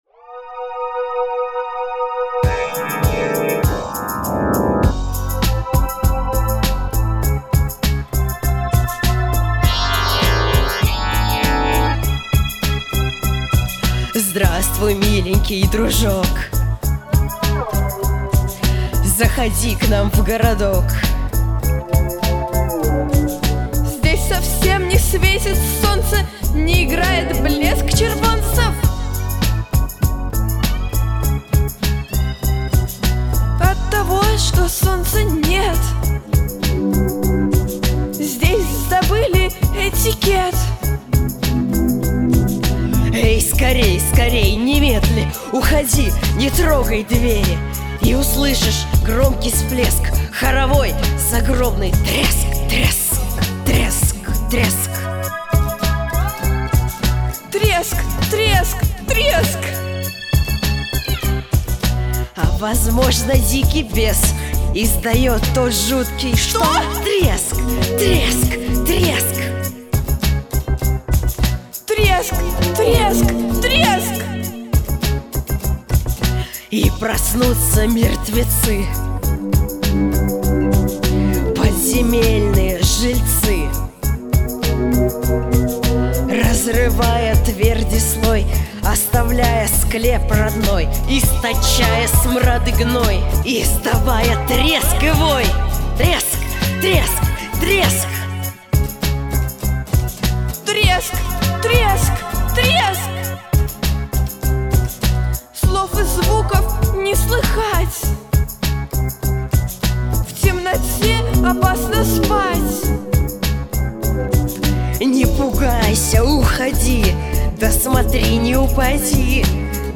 Записано в студии Easy Rider в октябре–декабре 2023 года